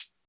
Closed Hats
old southside hh.wav